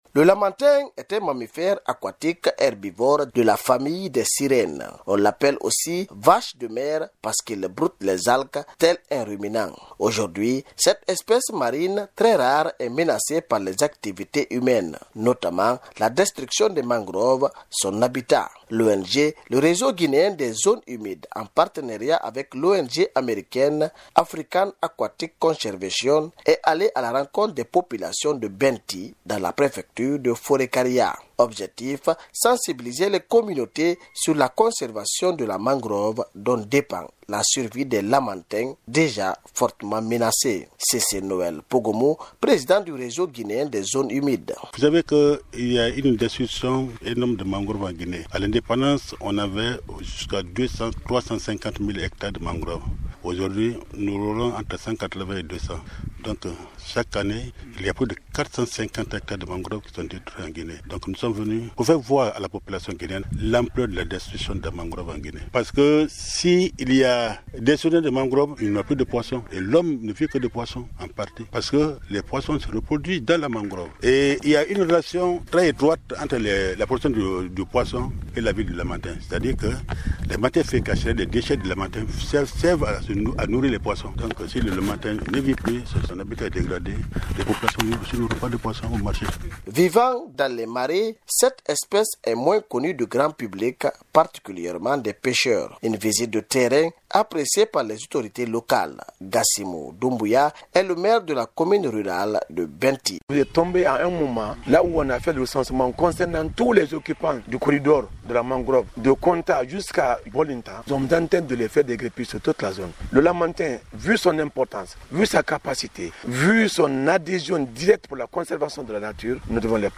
Une visite de terrain au port négrier de Benty dans la préfecture de Forécariah pour sensibiliser les populations a eu lieu en début de semaine.